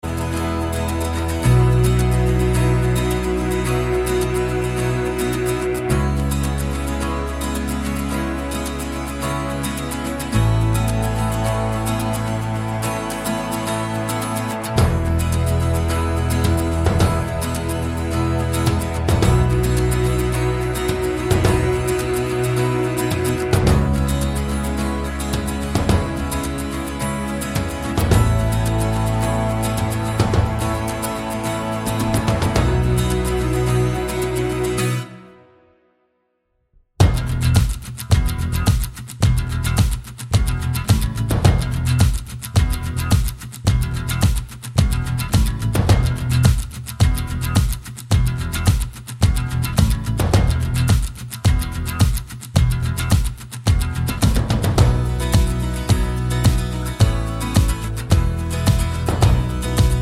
Single Version With Lead Girl Rock 4:15 Buy £1.50